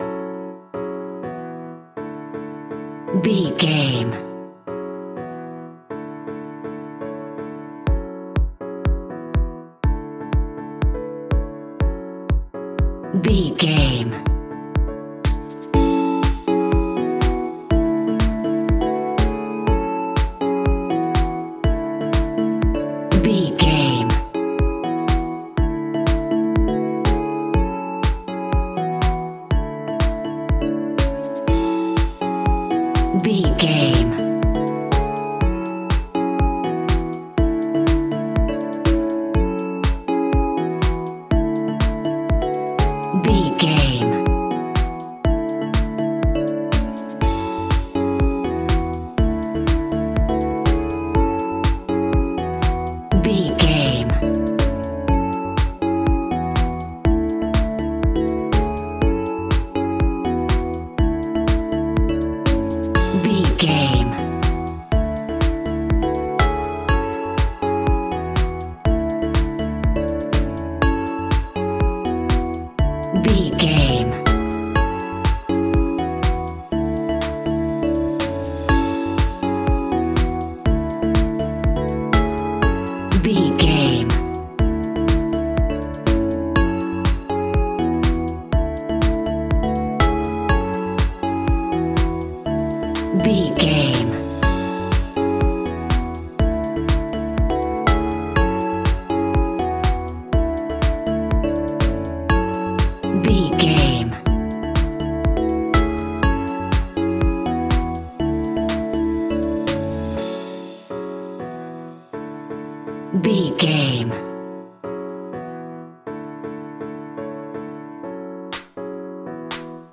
Aeolian/Minor
F#
groovy
cheerful/happy
piano
drum machine
synthesiser
electro house
funky house
synth leads
synth bass